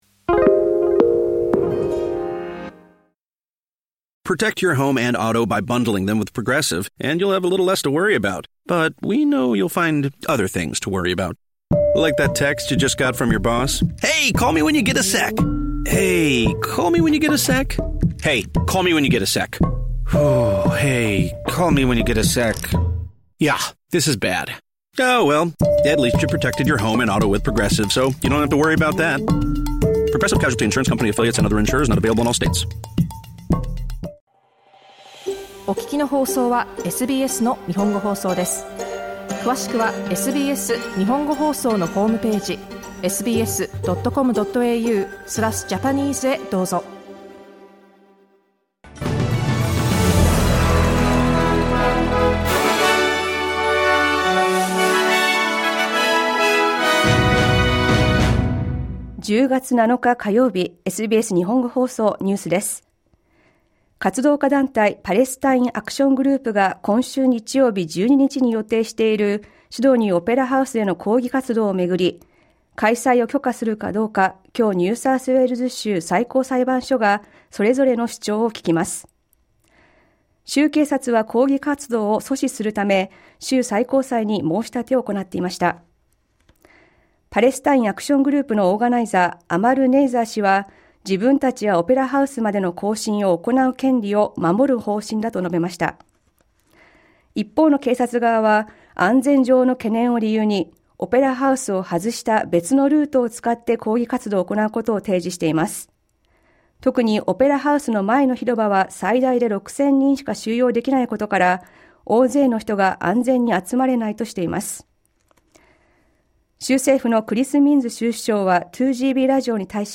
SBS日本語放送ニュース10月7日火曜日